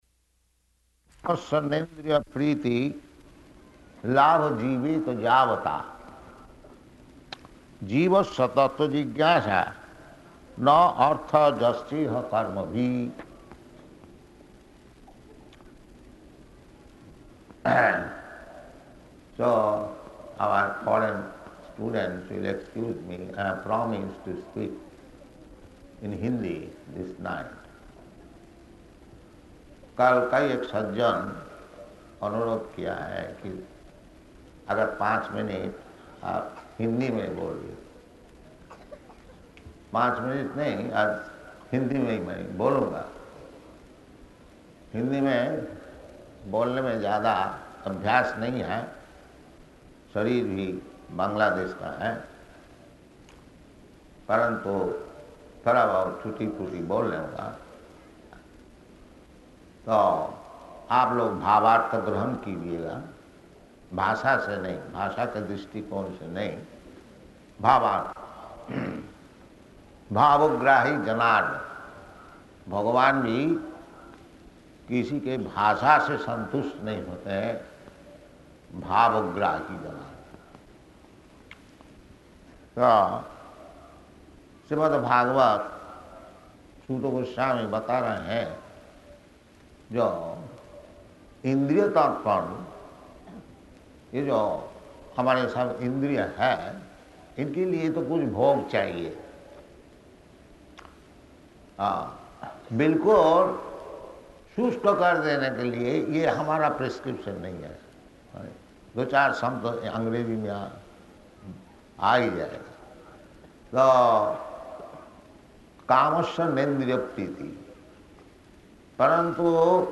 Location: Delhi
Śrīmad-Bhāgavatam 1.2.9–10 Lecture at Pandal